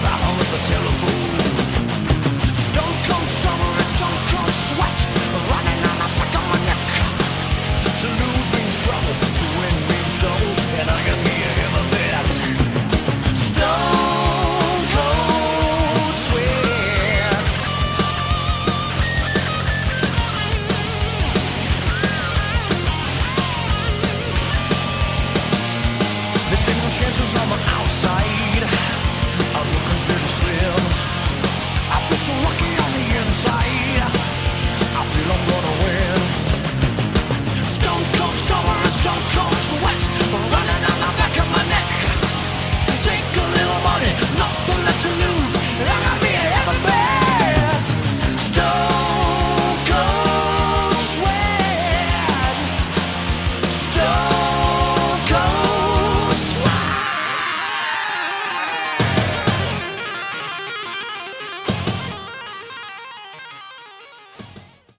Category: Melodic Metal
guitar, keyboards and vocals